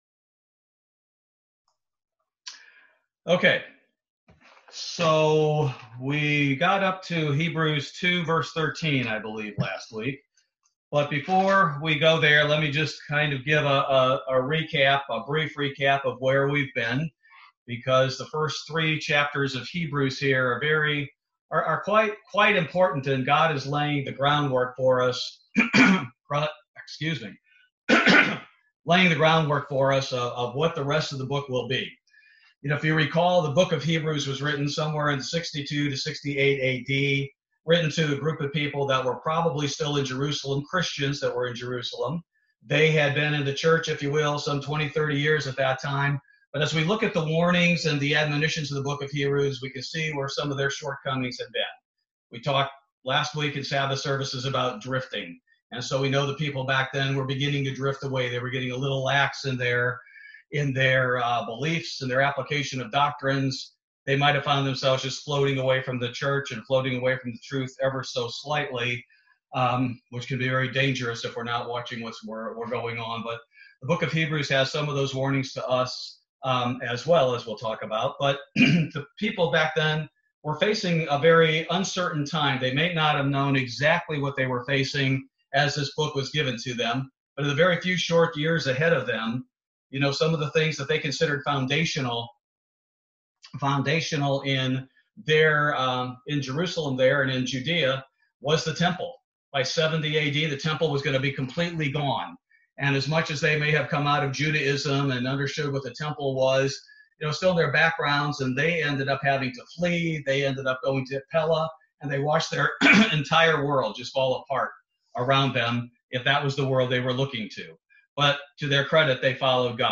Bible Study - November 11, 2020